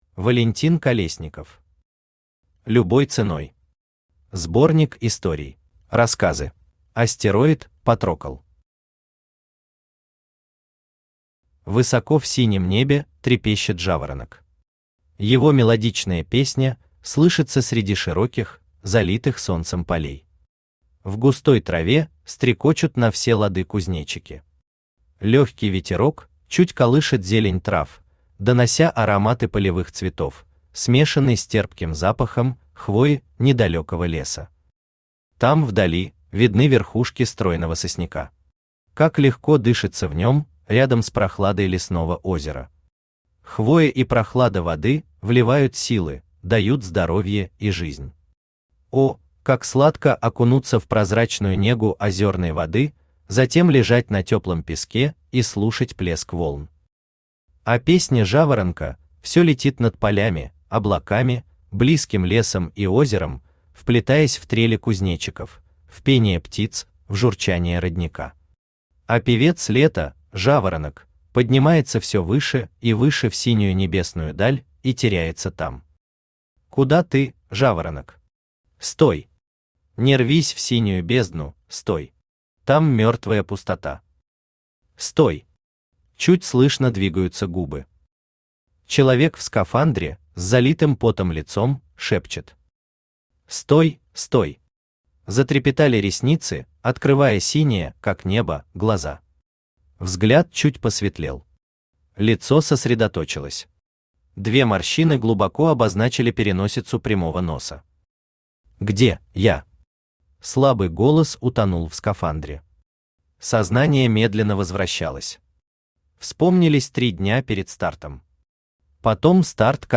Сборник историй Автор Валентин Колесников Читает аудиокнигу Авточтец ЛитРес.